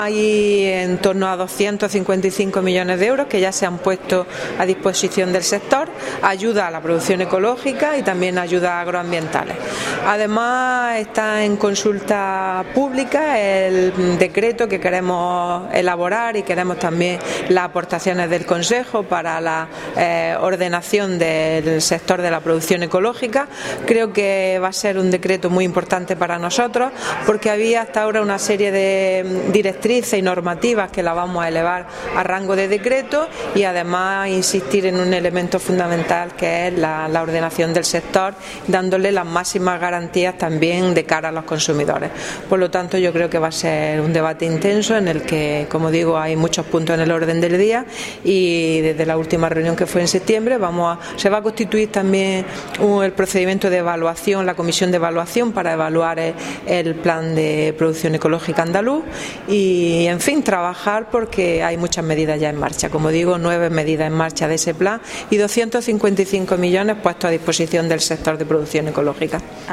Declaraciones consejera CAPE